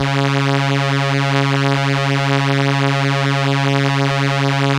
Index of /90_sSampleCDs/Keyboards of The 60's and 70's - CD1/STR_Elka Strings/STR_Elka Violins
STR_ElkaVlsC#4.wav